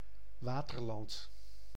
Waterland (Dutch pronunciation: [ˈʋaːtərlɑnt]
Nl-Waterland.ogg.mp3